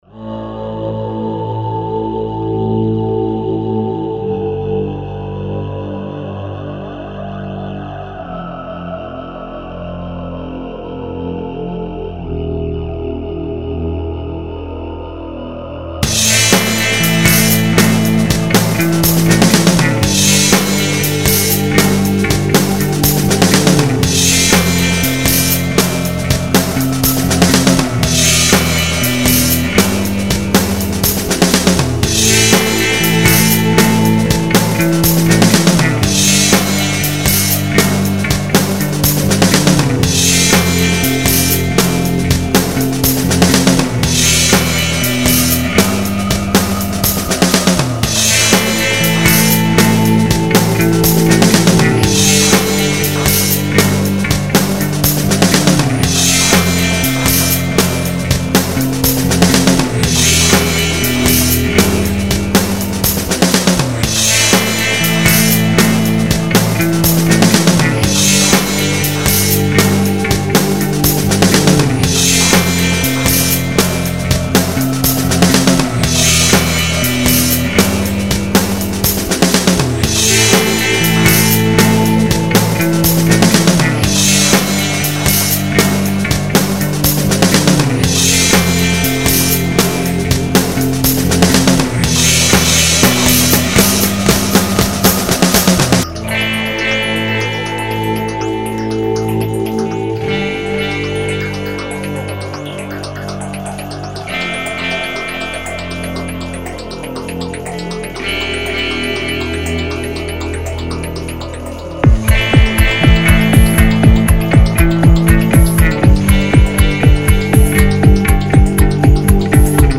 dark and moody
House
Techno
Leftfield/noise